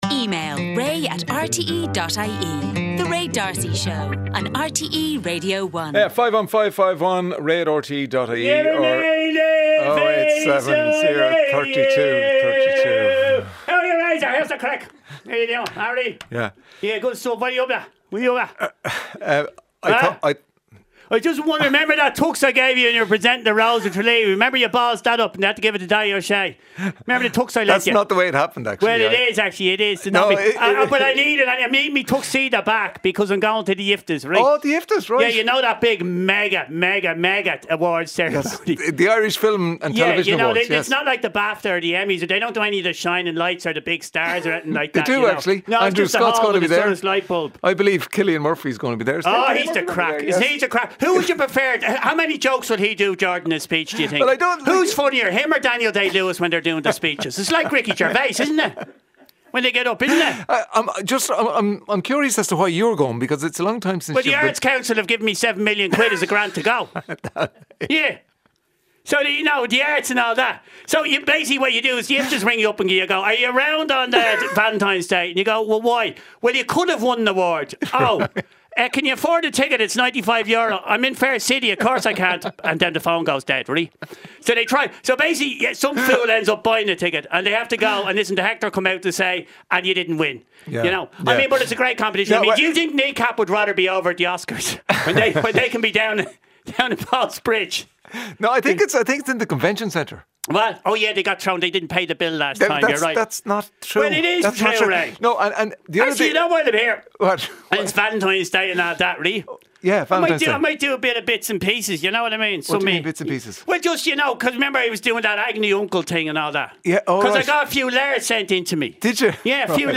Highlights from the daily radio show with Ray D'Arcy. Featuring listeners' stories and interviews with authors, musicians, comedians and celebrities.